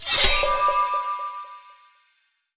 pgs/Assets/Audio/Magic_Spells/special_item_popup_02.wav at master
special_item_popup_02.wav